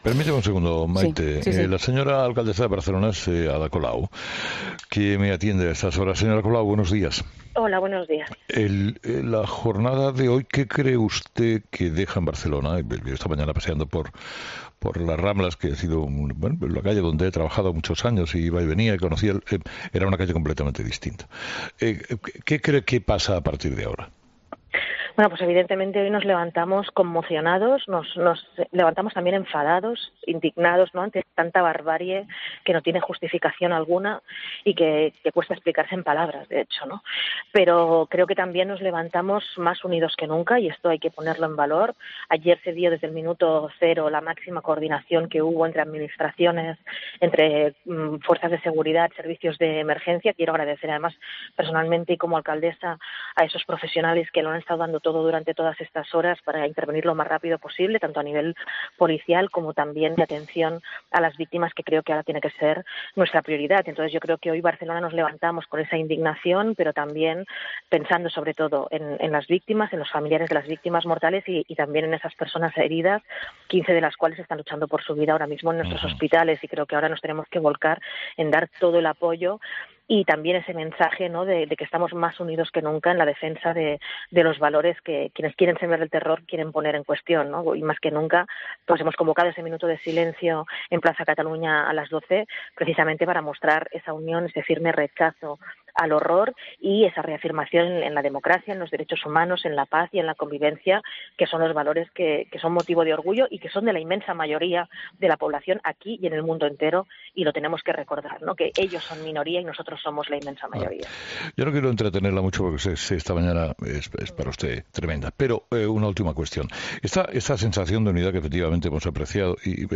Ada Colau, alcaldesa de Barcelona, en 'Herrera en COPE'